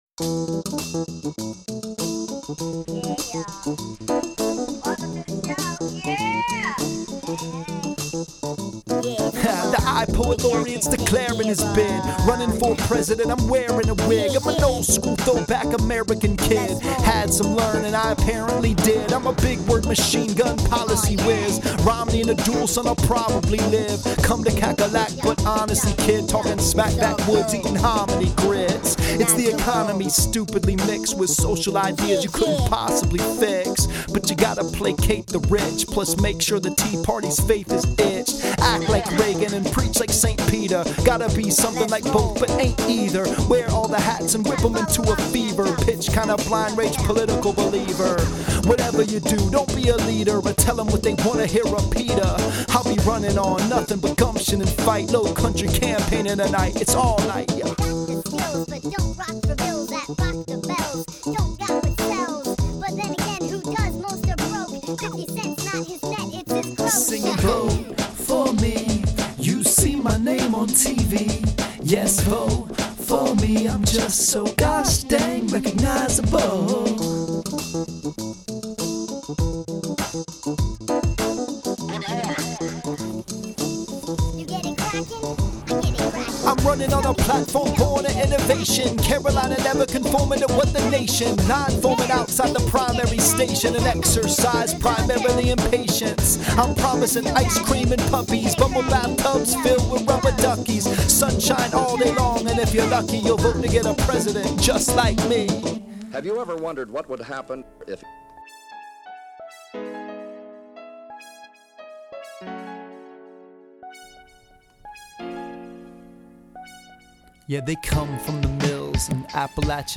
But, sometimes you just have to pull the trigger and do a SC primary rap, right?
super fresh beat right?